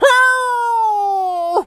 wolf_hurt_02.wav